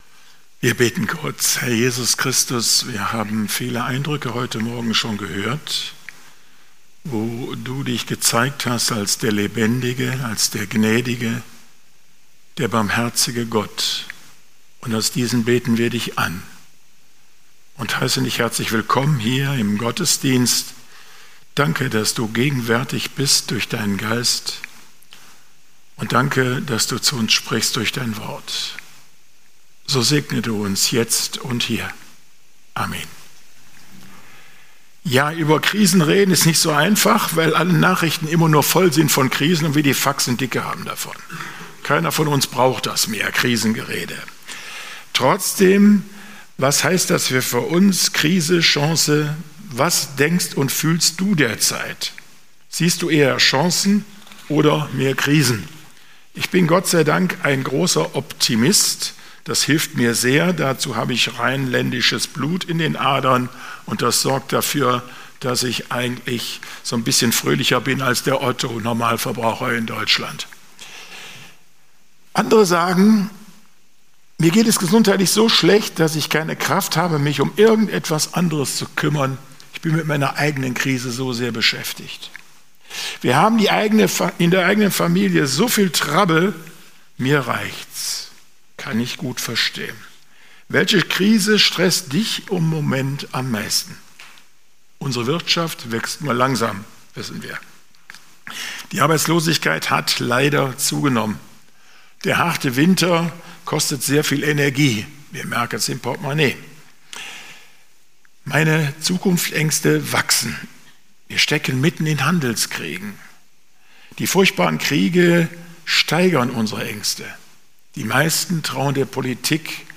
Predigten - FeG Steinbach Podcast